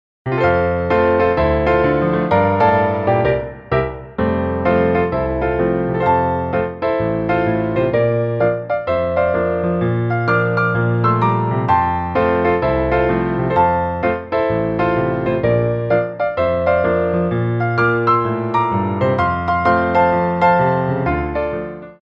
Grand Battements
4/4 (8x8)